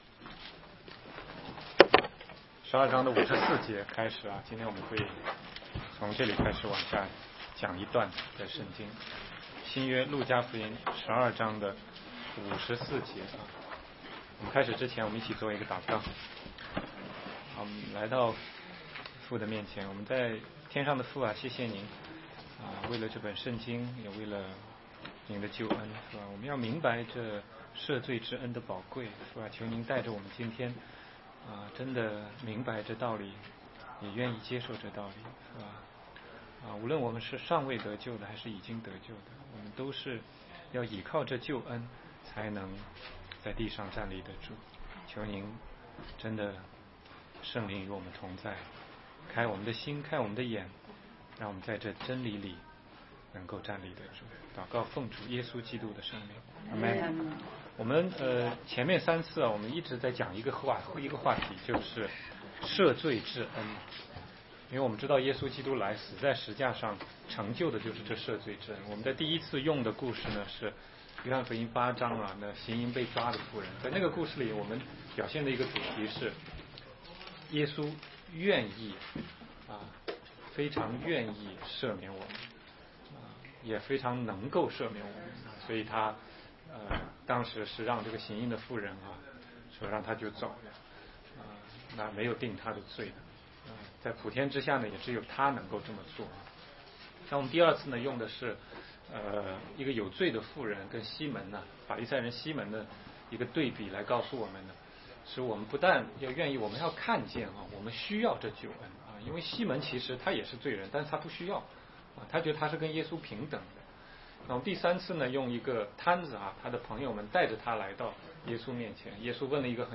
16街讲道录音 - 救恩不是可有可无